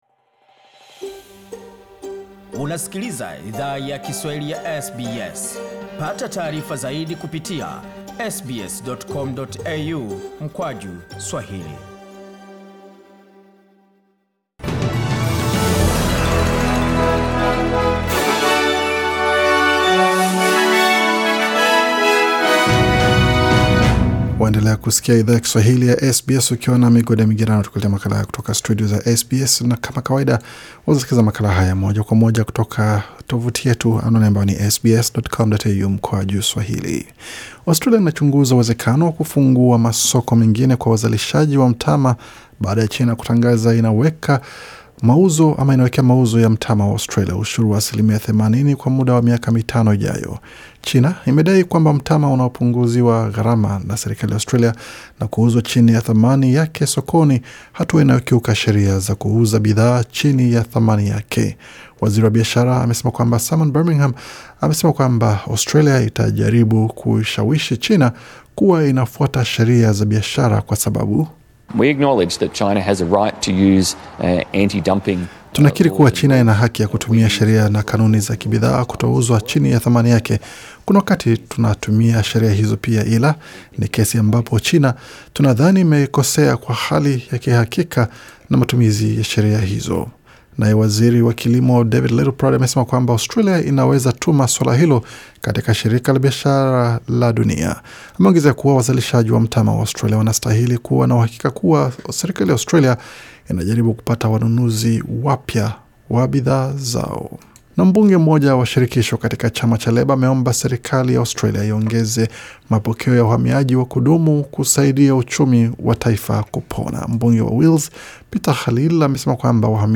Taarifa za habari 19 Mei 2020